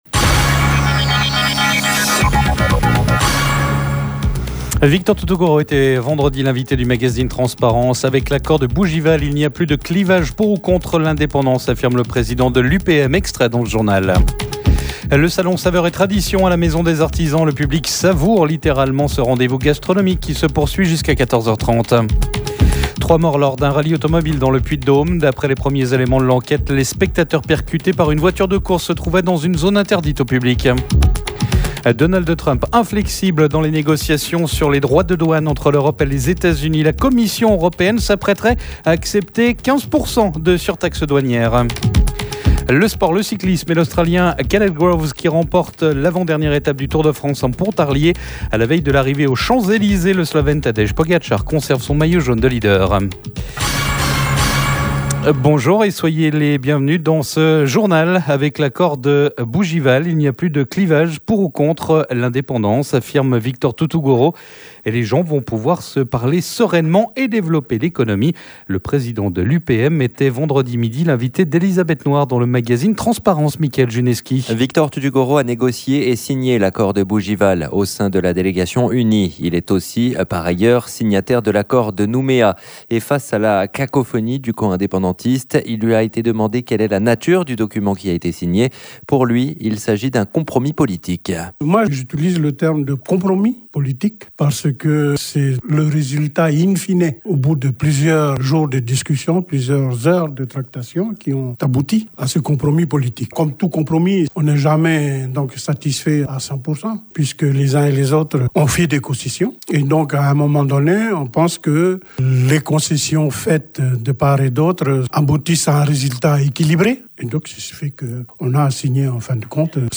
Info-weekend, votre grand journal du weekend, pour tout savoir de l'actualité en Calédonie, en métropole et dans le Monde.